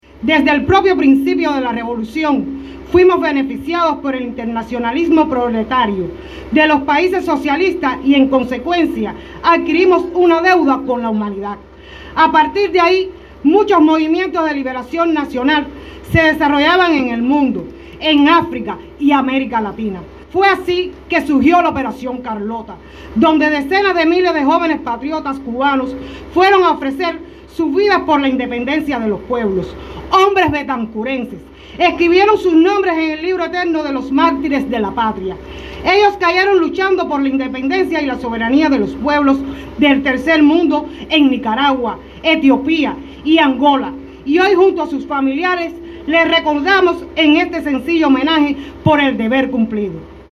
PEDRO BETANCOURT.- En un emotivo acto político-cultural de homenaje a quienes ofrendaron su vida en nombre de la causa internacionalista, hasta el panteón de héroes del cementerio betancourense se efectuó la peregrinación correspondiente a la Operación Tributo.